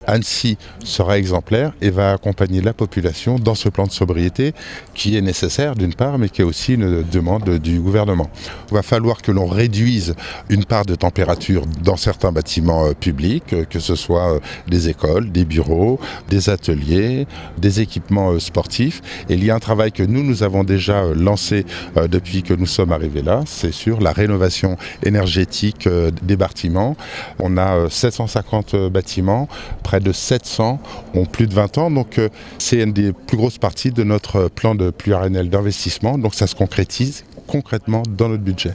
François Astorg, maire d’Annecy au micro